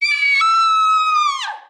SCREAM.wav